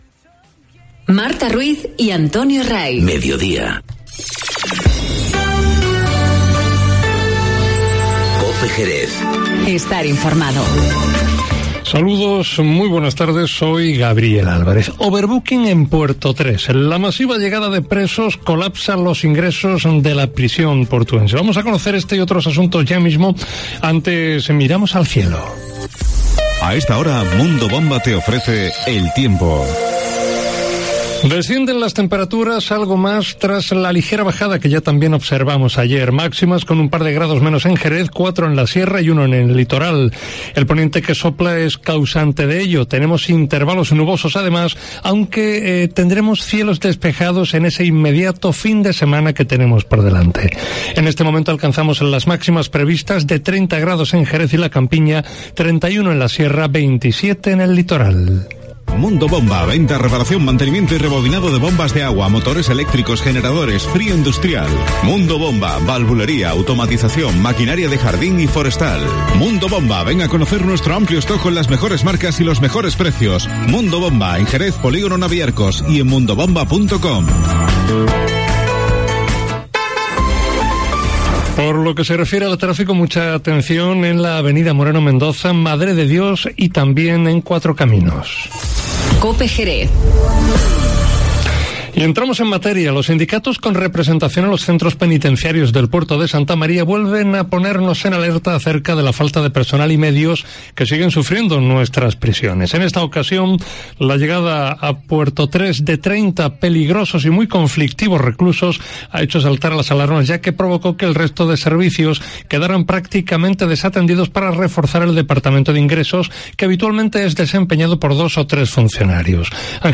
Informativo Mediodía COPE en Jerez 26-07-19